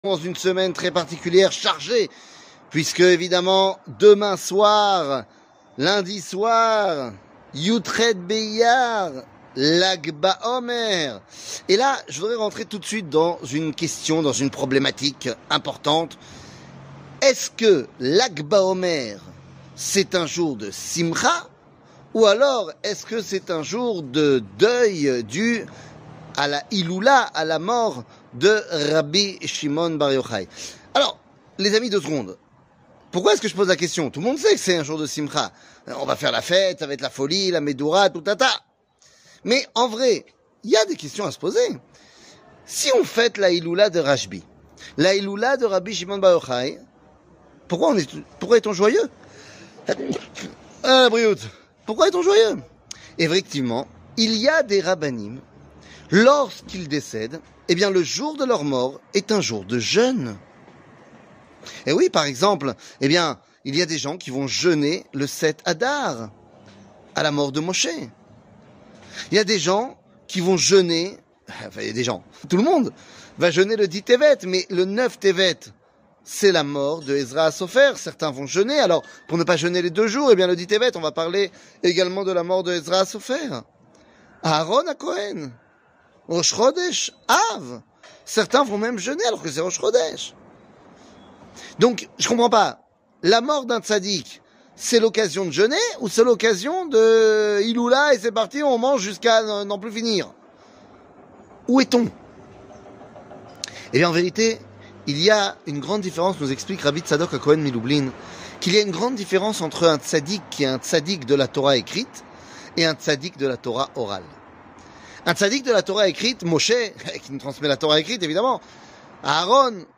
שיעור מ 07 מאי 2023 05MIN הורדה בקובץ אודיו MP3 (5.16 Mo) הורדה בקובץ וידאו MP4 (11.49 Mo) TAGS : שיעורים קצרים